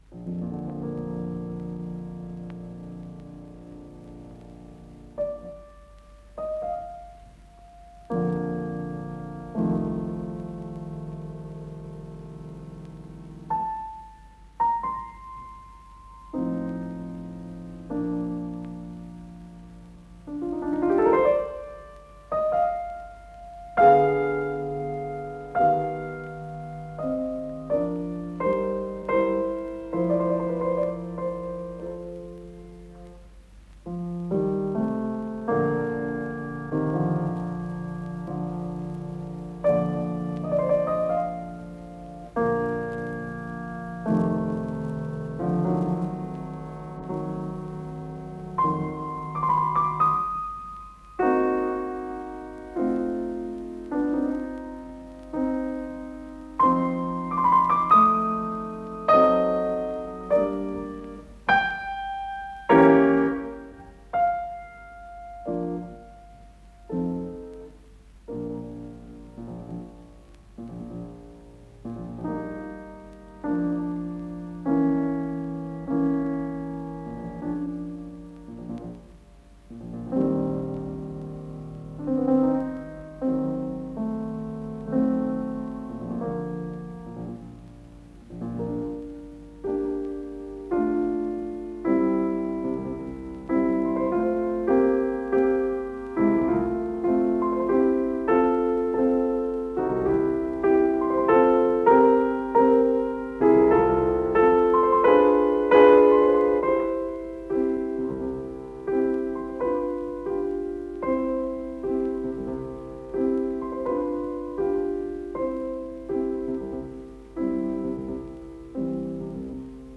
Исполнитель: Святослав Рихтер - фортепиано
Ре минор Код П-02048 Фонд Норильская студия телевидения (ГДРЗ) Редакция Музыкальная Общее звучание 00:23:38 Дата переписи 23.12.1969 Дата добавления 29.04.2025 Прослушать